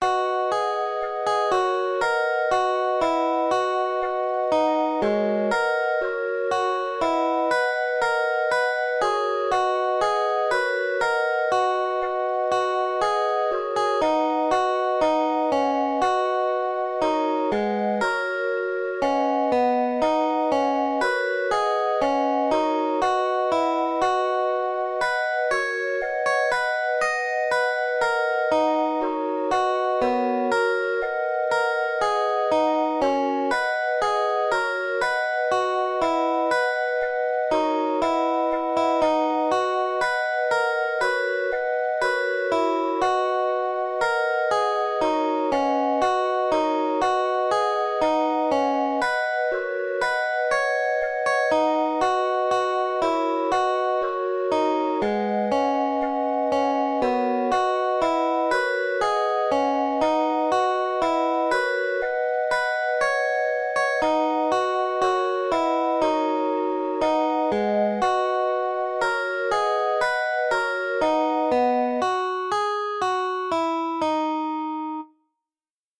Those that are just a piano are the MIDI processed through LMMS without any fiddling with the instruments.